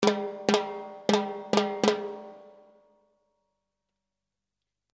Timbales fills in 98 bpm
The timbales are with light reverb and they are in 98 bpm.
This package contains real timbales fills playing a variety of fills in 98 bpm.
The timbales were recorded using “ AKG C-12 ” mic. The timbales were recorded mono but the files are stereo for faster workflow. The reverb is stereo on the mono timbales.